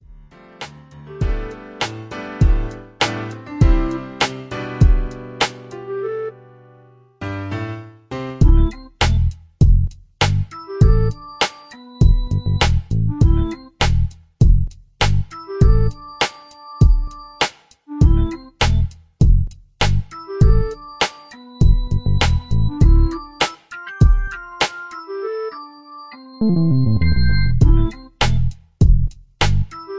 Contemporary Jazz Beat